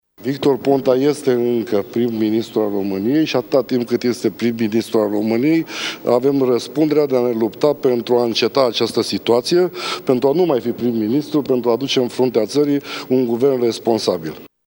Prim-vicepreşedintele liberal Teodor Atanasiu a declarat ca Executivul condus de Victor Ponta a încălcat Constituţia şi a săvârşit abuzuri ‘inimaginabile’.